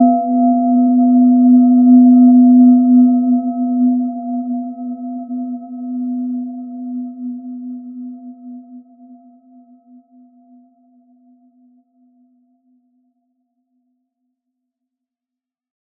Gentle-Metallic-4-B3-mf.wav